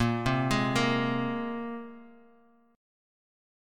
Bb7sus2 chord